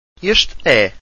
Eshte eh… (when introducing a male)